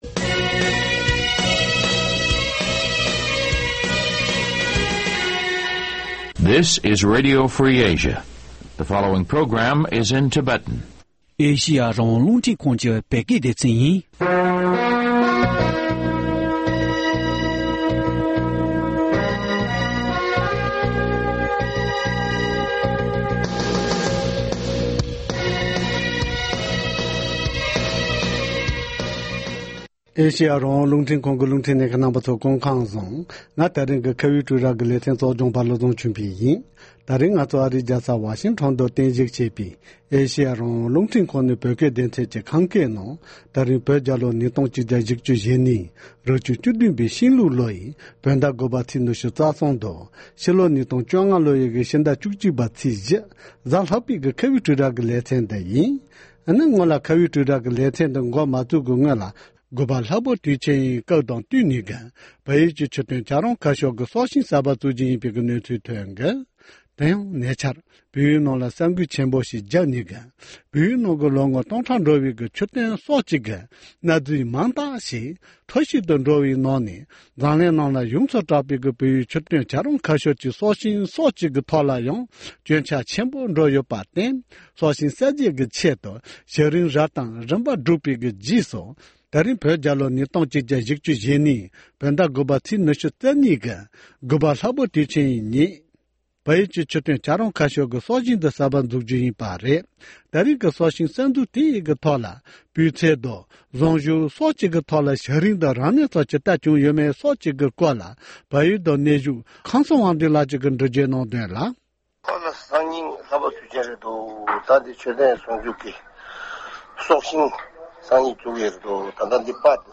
༄༅༎ དེ་རིང་གི་ཁ་བའི་གྲོས་རྭ་ཞེས་པའི་ལེ་ཚན་ནང་དུ། སྲིད་སྐྱོང་དང་སྤྱི་འཐུས་ཀྱི་སྔོན་འགྲོའི་འོས་བསྡུའི་གྲངས་བཤེར་སྐབས་སུ་དཀའ་ངལ་ཇི་ལྟར་འཕྲད་ཡོད་མེད་དང་མ་འོངས་དཀའ་གནད་དེའི་ཐད་ལ་འོས་བསྡུ་ལྷན་ཁང་དང་། འོས་འཕེན་པ་གཉིས་ཀའི་ཐོག་ནས་འགན་ཇི་ལྟར་ཁུར་དགོས་མིན་སོགས་ཡར་རྒྱས་ཇི་ལྟར་བཏོང་དགོས་མིན་བཅས་ཀྱི་སྐོར་གླེང་མོལ་ཞུས་པ་ཞིག་གསན་རོགས་གནང་།